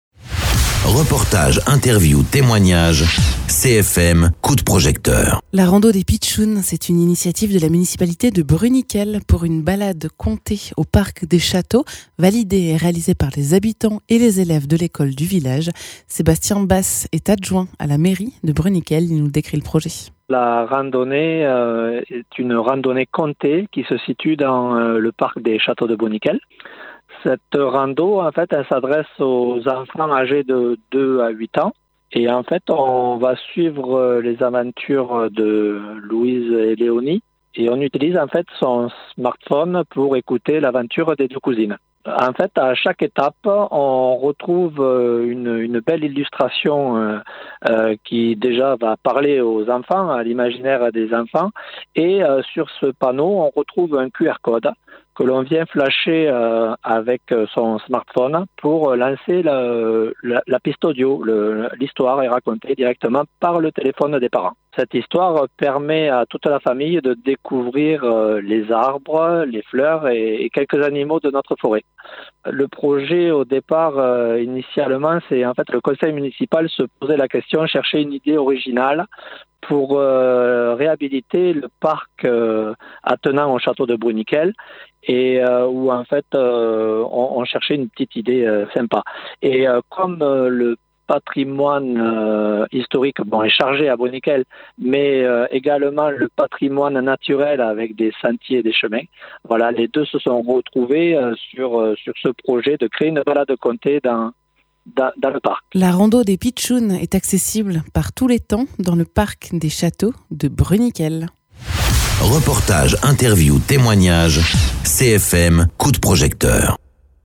Interviews
Invité(s) : Sébastien Basse, adjoint à la mairie de Bruniquel